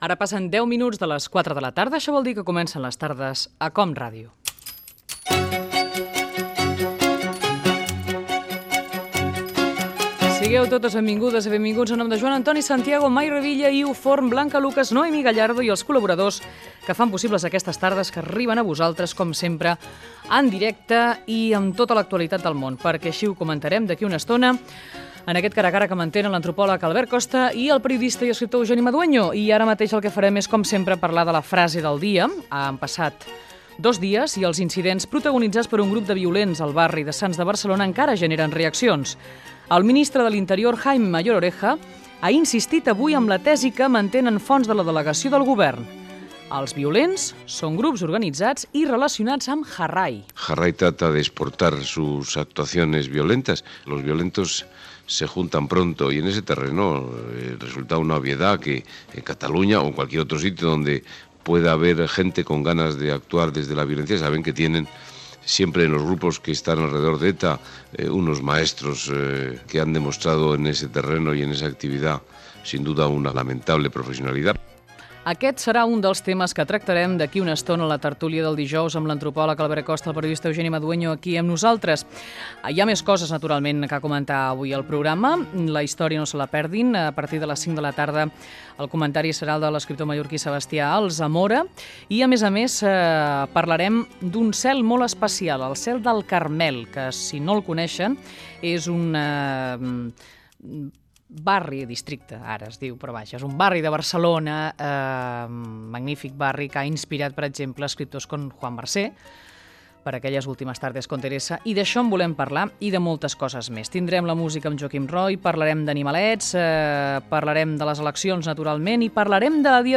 Hora, equip, presentació. la frase del dia, sumari del programa, indicatiu, record d'un tema tractat el dia abans relacionat amb la SIDA, indicatiu.
Entreteniment
FM